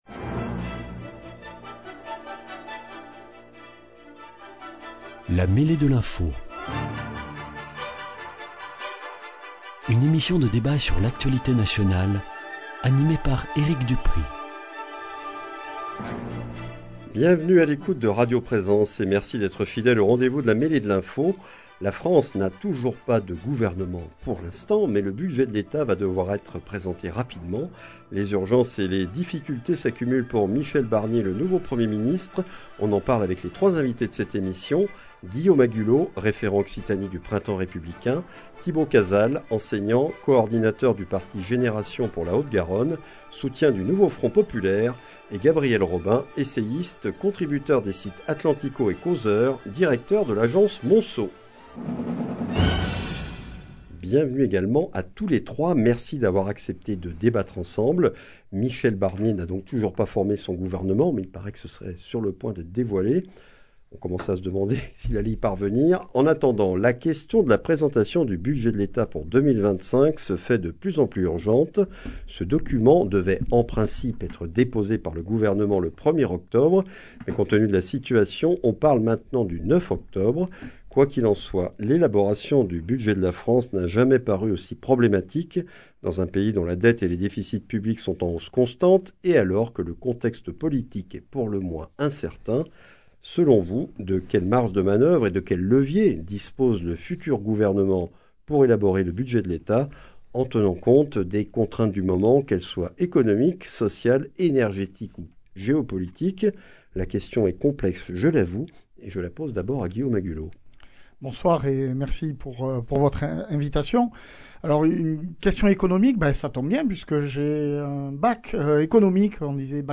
Dans ce numéro, nous débattons autour des questions que pose l’élaboration du budget de la France, avant de revenir sur la polémique entre François Ruffin et les élus de LFI.